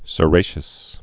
(sə-rāshəs)